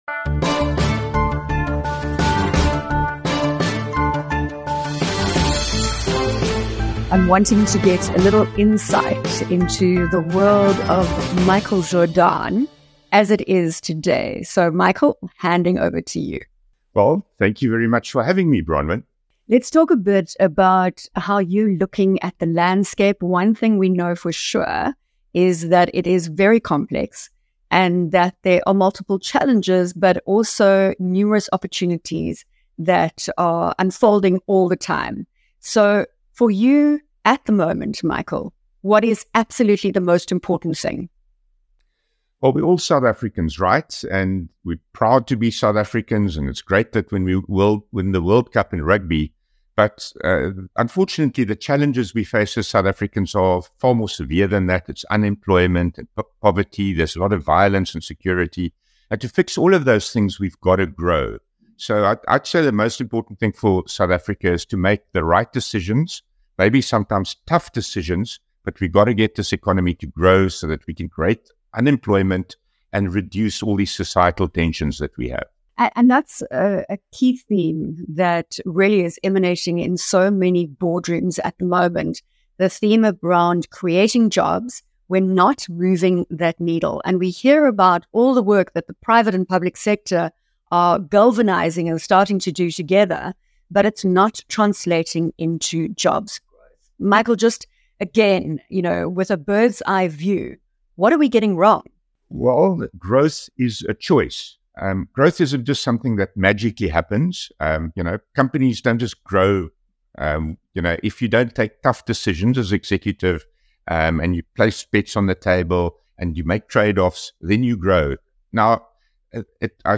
In this Biznews interview he shares his view on the state of the world. From the US election to the GNU and the South African economy. He also gives updates on the businesses he is involved in namely Bank Zero and Rain.